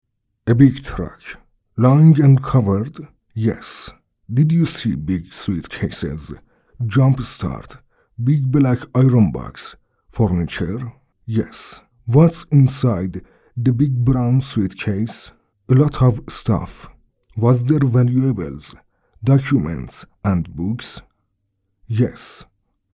Male
Senior
English (Local accent)
English-Persian-accent